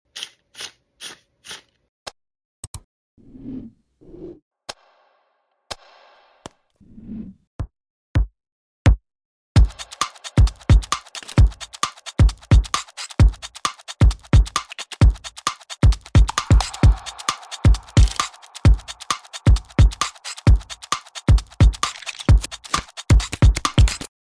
back-to-school themed tracks with gum and school sounds